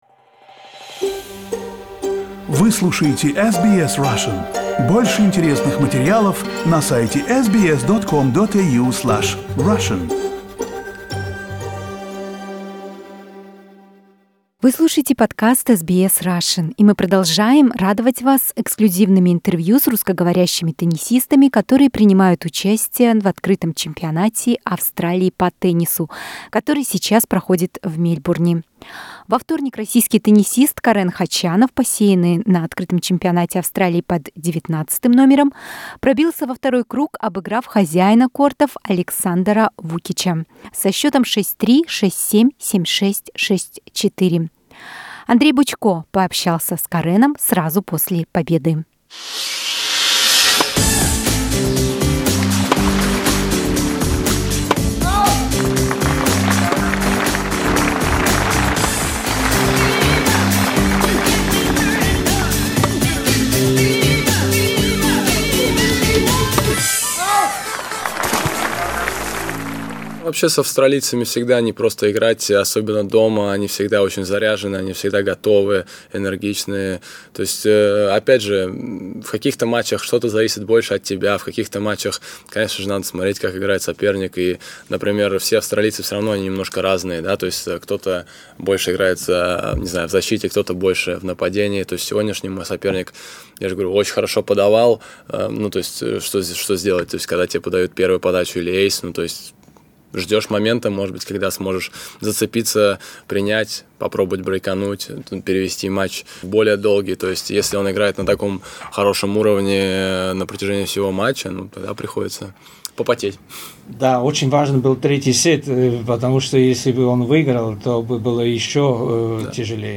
Российский теннисист Карен Хачанов, посеянный на Открытом чемпионате Австралии под 19-м номером, пробился во второй круг, обыграв хозяина кортов Александара Вукича. Экслюзивное интервью для SBS Russian сразу после вчерашнего поединка.